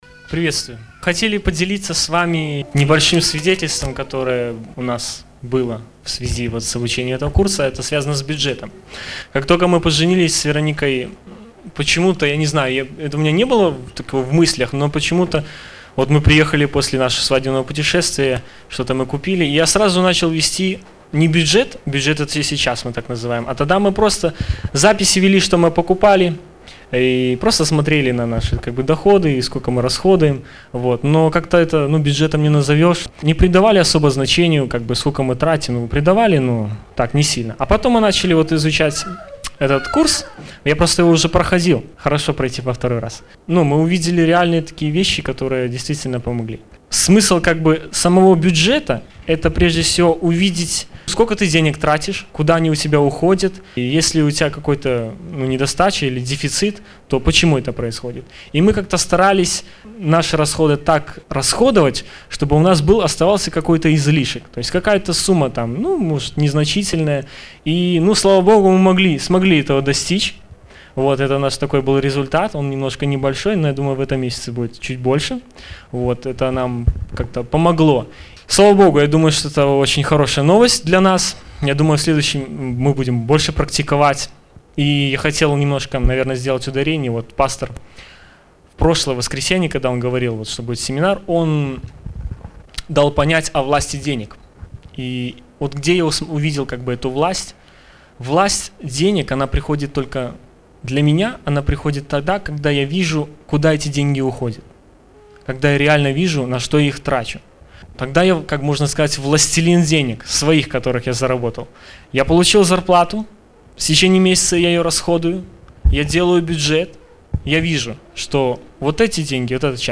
В дискуссии принимали участие все.
Мы выкладываем по мере готовности аудио-версию семинара, прошедшего в субботу 21 апреля 2012 года в стенах церкви «Святая Иоппия».